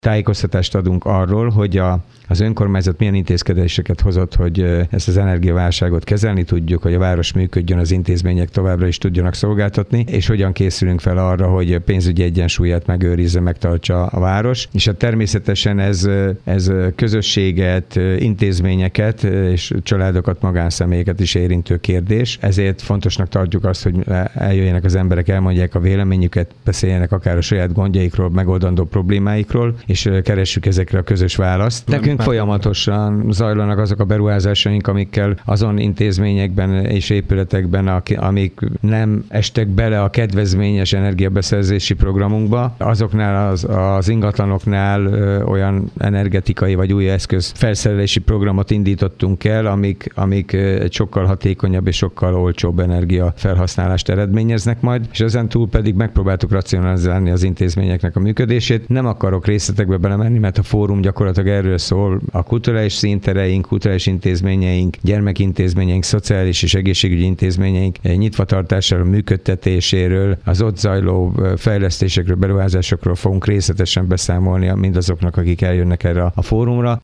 Ugyancsak holnap 17 órakor tartanak Dabason is közmeghallgatást, ahol a lakosság tájékoztatást kaphat az energiaválság miatti intézkedésekről. Kőszegi Zoltán polgármestert hallják.